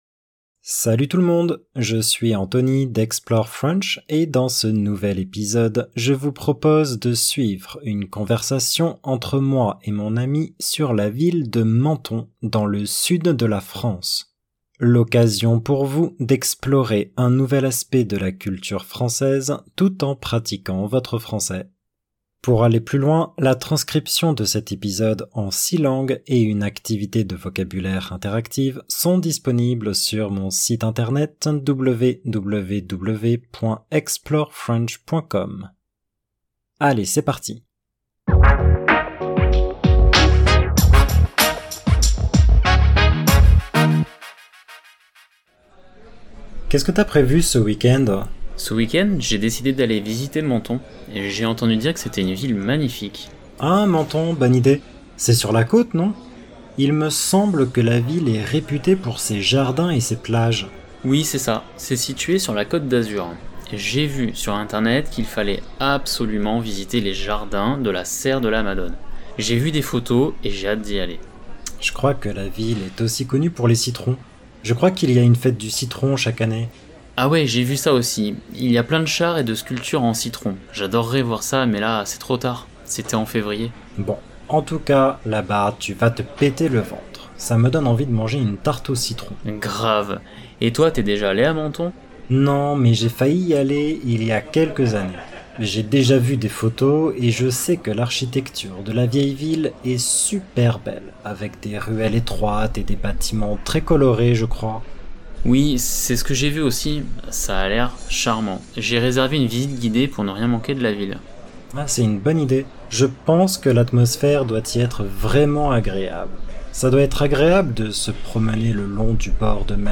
and have no regional accent.